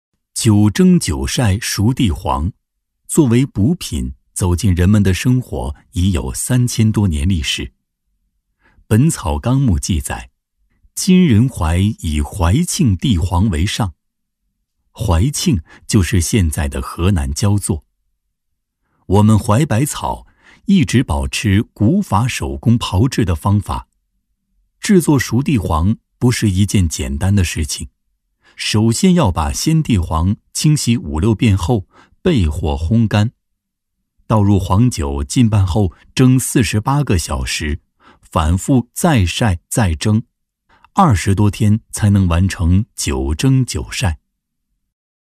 男14-纪录片《九蒸九晒熟地黄》-舌尖讲述感模仿李立宏
男14-透亮故事感 沉稳大气
男14-纪录片《九蒸九晒熟地黄》-舌尖讲述感模仿李立宏.mp3